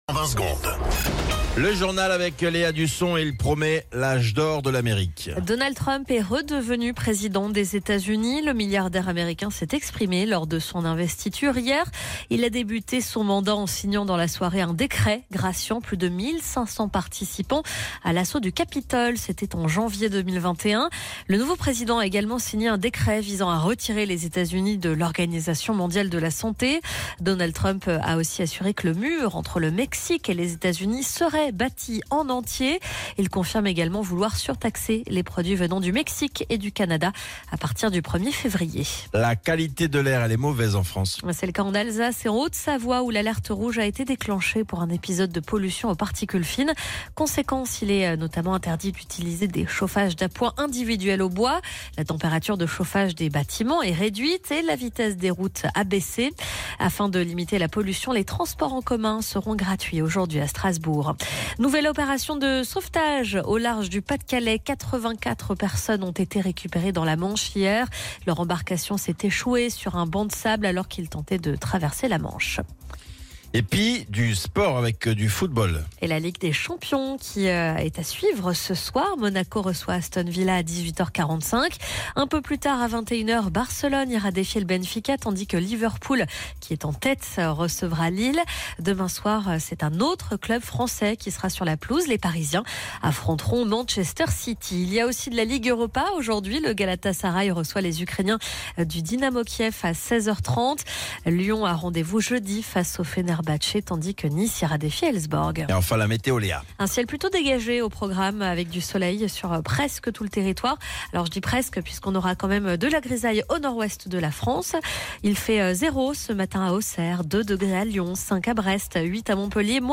Flash Info National 21 Janvier 2025 Du 21/01/2025 à 07h10 .